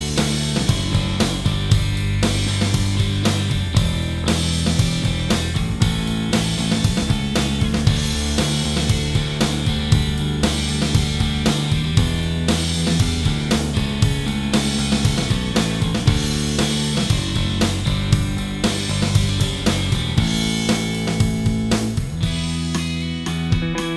Minus Stereo Lead And Solo Guitars Rock 3:36 Buy £1.50